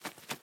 sounds / mob / parrot / fly6.ogg
fly6.ogg